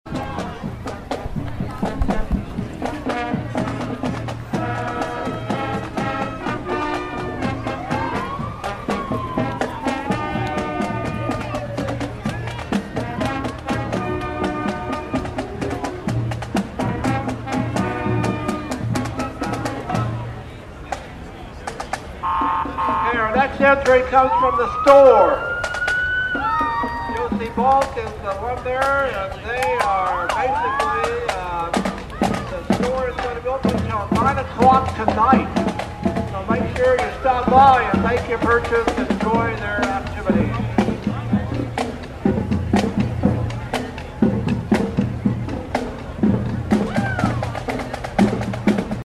The Riley County High School Marching Band flawlessly performed throughout the parade; with equipment from the Leonardville Fire Station coming behind them with lights and occasional sirens.